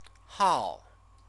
chinese_characters_hao-5_hao-5.mp3